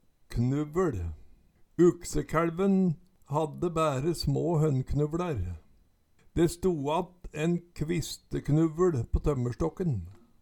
Høyr på uttala Ordklasse: Substantiv hankjønn Attende til søk